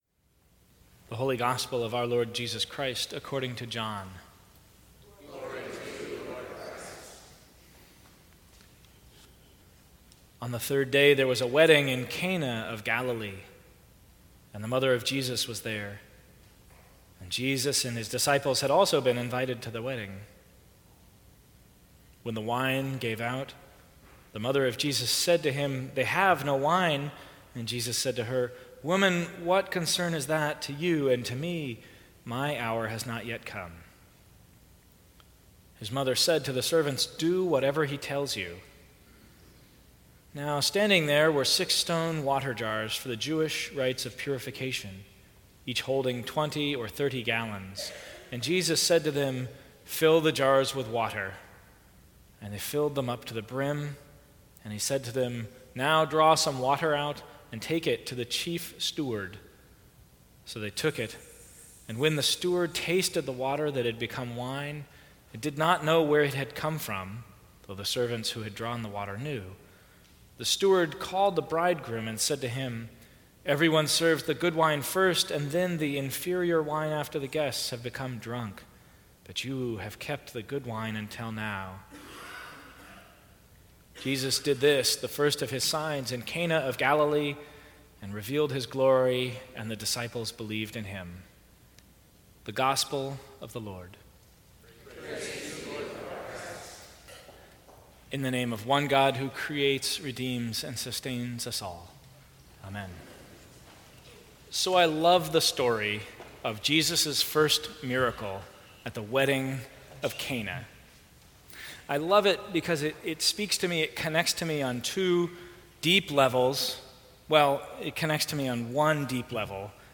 Sermons from St. Cross Episcopal Church Join the feast and be fed Oct 16 2016 | 00:14:02 1x 00:00 / 00:14:02 Subscribe Share Apple Podcasts Spotify Overcast RSS Feed Share Link Embed